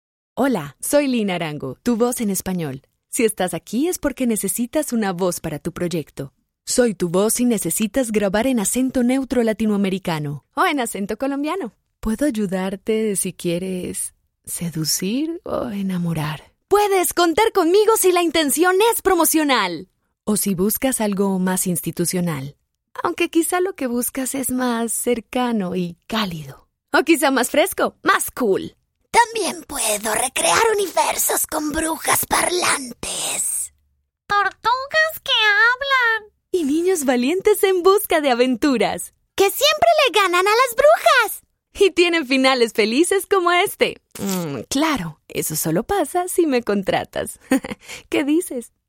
Si necesitas acento neutro latinoamericano, castellano o colombiano, esa es mi voz.
Sprechprobe: Industrie (Muttersprache):